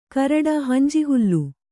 ♪ karaḍa hanji hullu